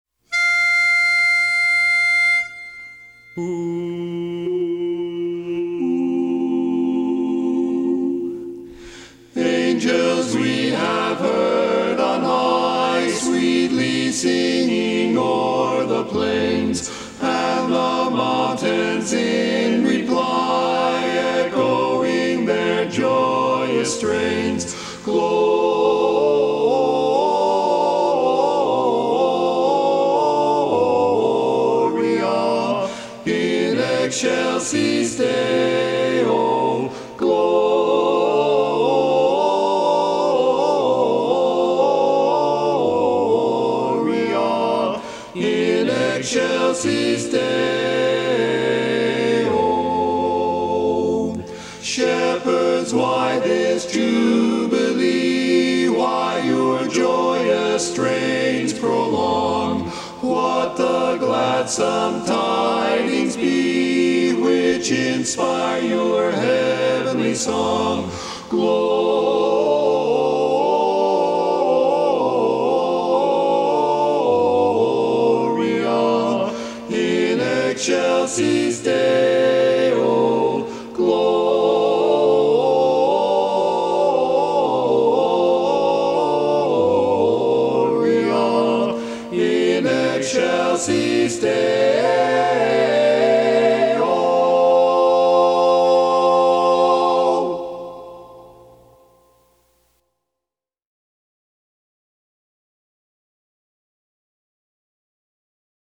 Barbershop
Bari